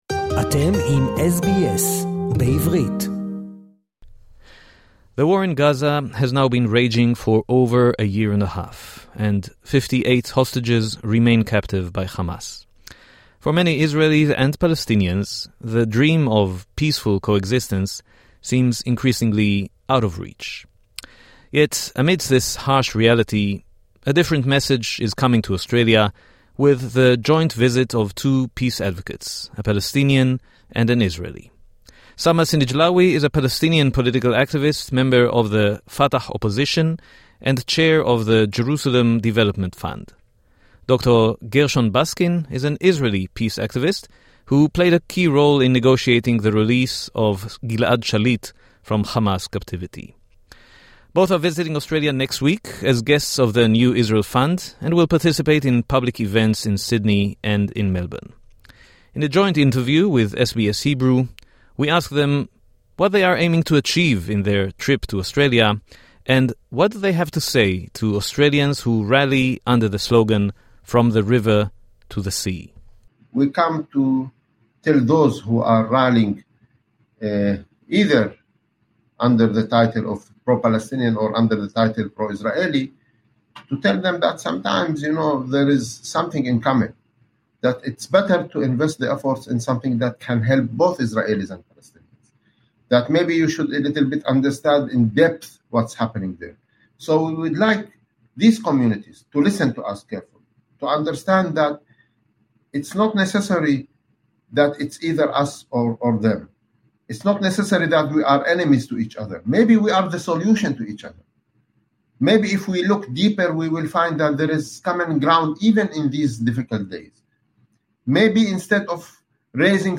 Two peace advocates - a Palestinian and an Israeli - are visiting Australia